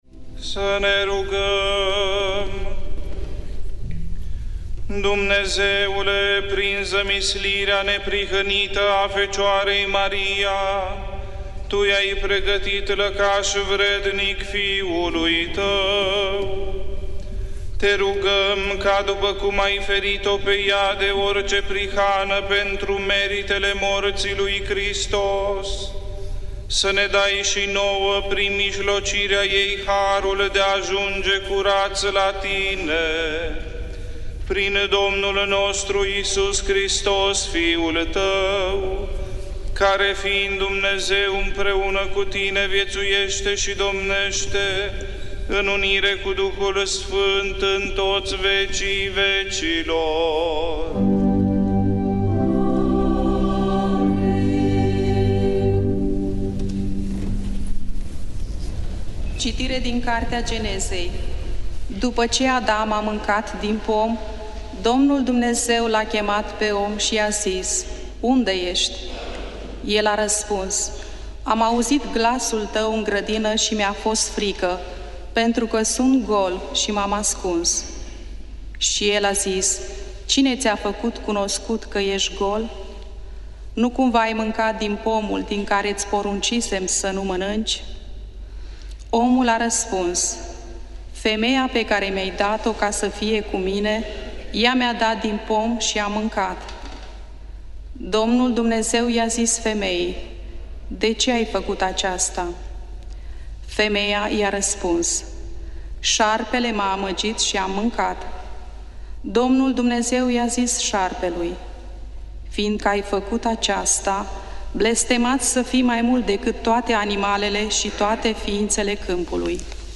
Biblioteca - Predici la Radio Iasi